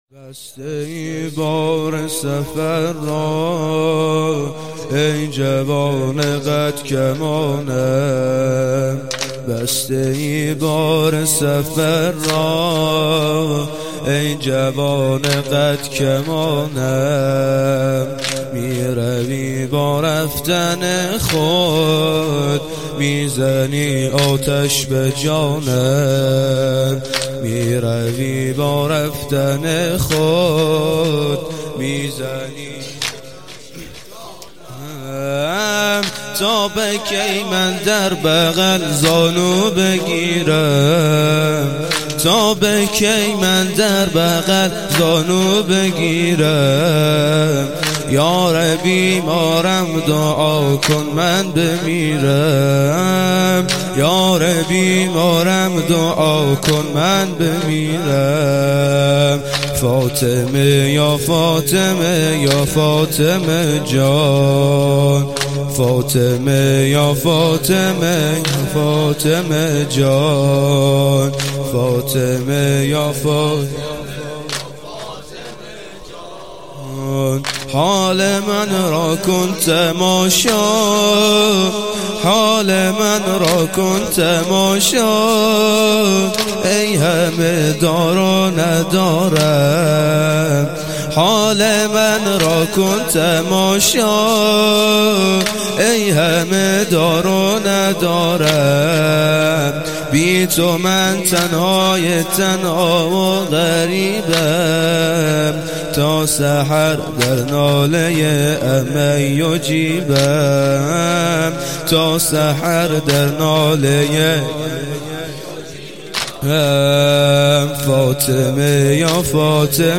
هیئت فاطمیون درق